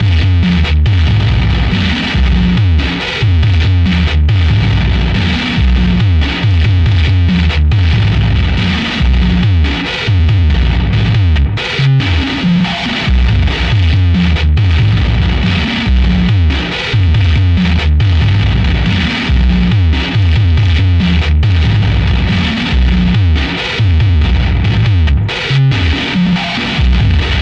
攻击循环2 " 75 bpm 原声新金属攻击循环1
我使用Acoustic kit来创建循环，并创建了8个不同顺序的循环，速度为75 BPM，长度为4/4的8个措施。
各种效果都是相当失真。
标签： 4 75bpm drumloop
声道立体声